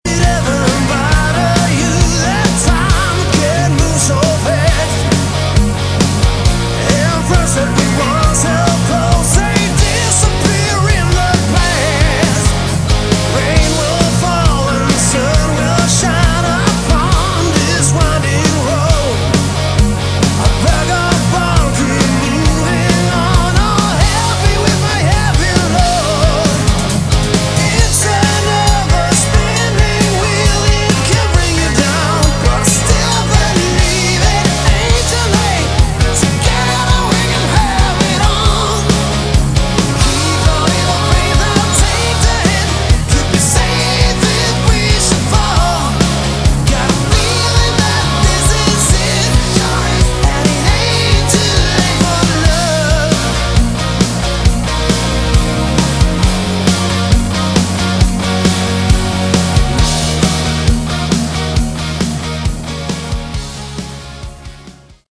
Category: Melodic Rock / AOR
lead vocals, guitar
guitar, keyboards, backing vocals
bass, backing vocals
drums, percussion
Really good AOR / melodic hard rock mix.